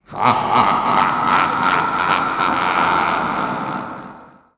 Sound: Spooky Laugh
Spooky-laugh.wav